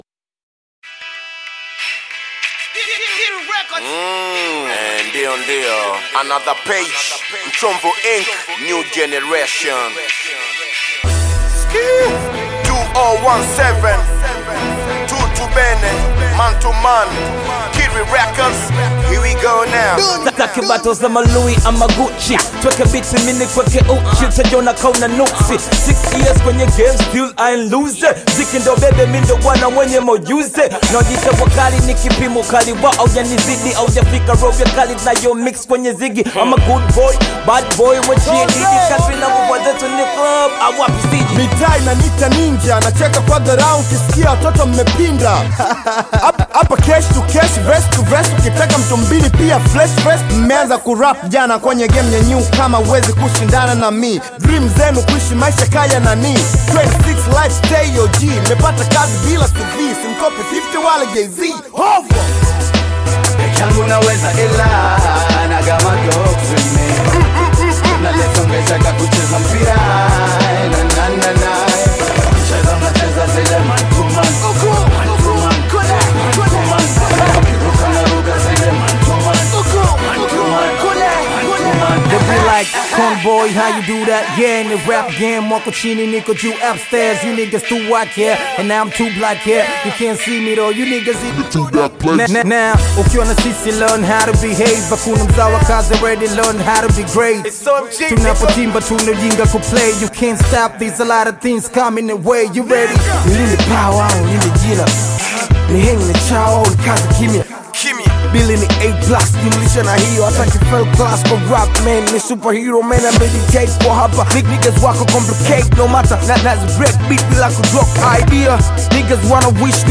-kutana na wakali wa HipHop